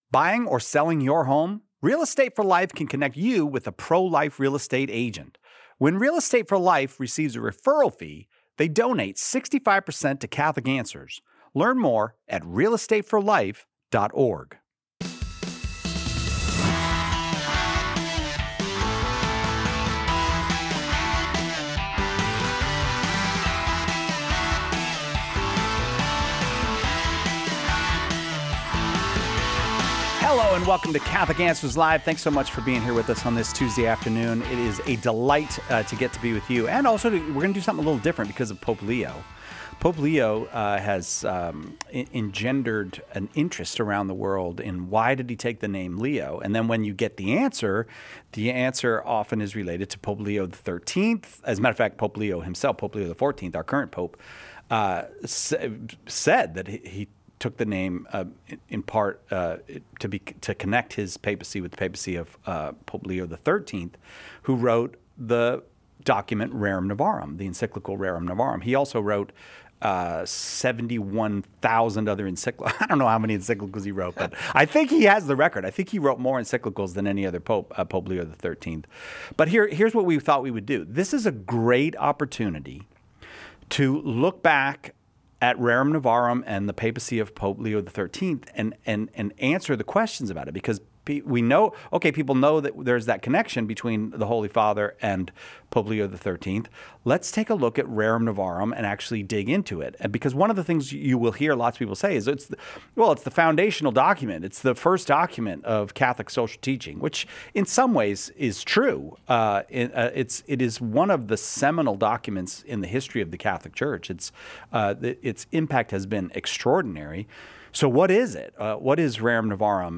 theologian